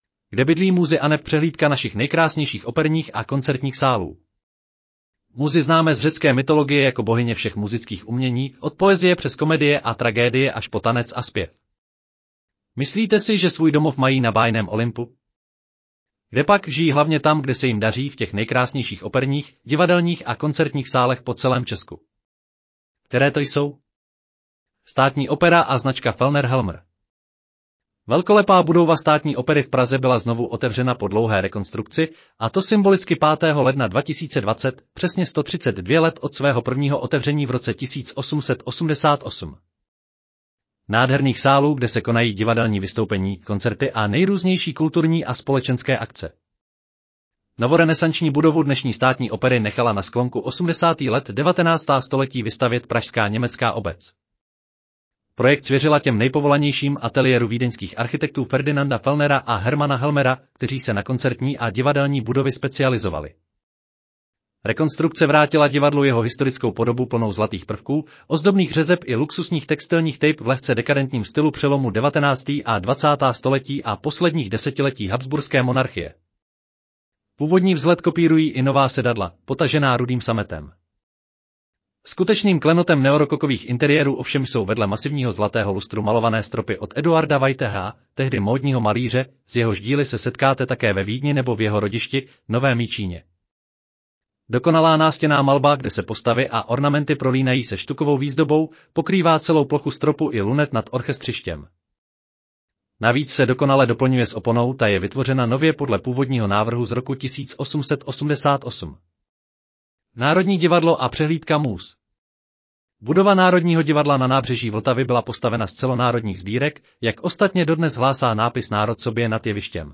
Audio verze článku Kde bydlí múzy aneb přehlídka našich nejkrásnějších operních a koncertních sálů